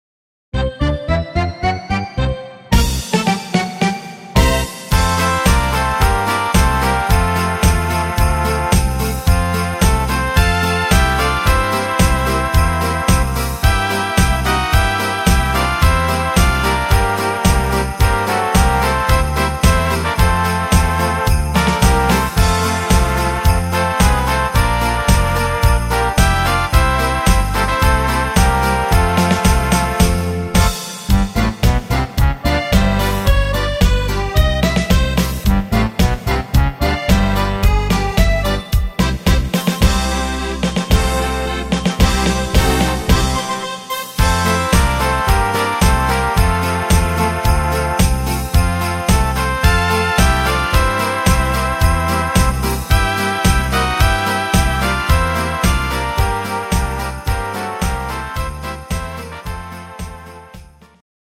instr.